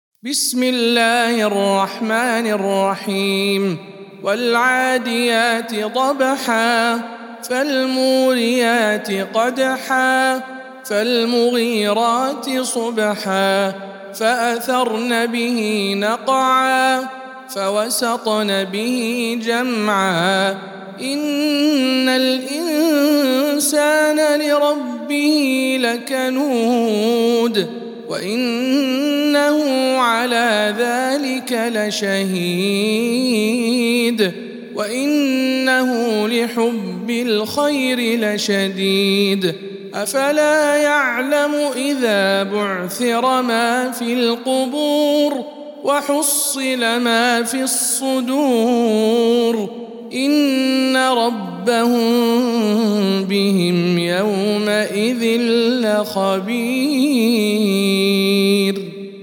سورة العاديات - رواية رويس عن يعقوب